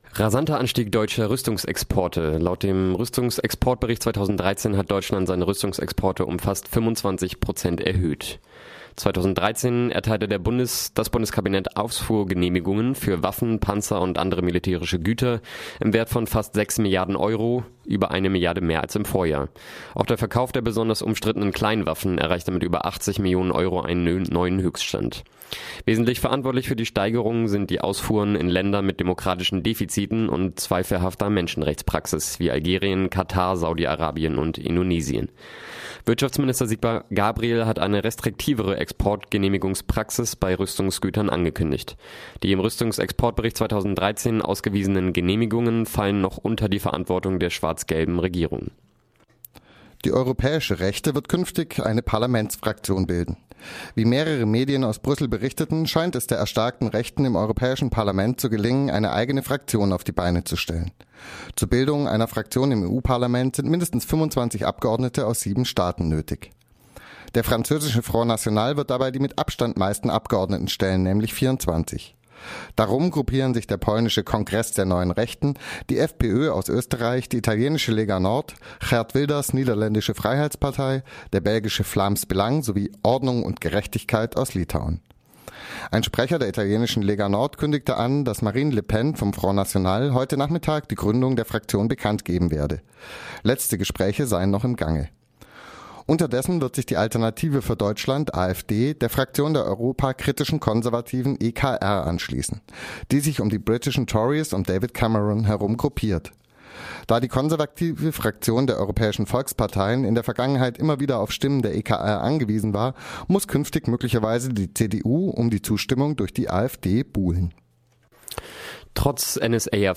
Focus Europa Nachrichten vom Donnerstag, den 12. Juni - 12.30 Uhr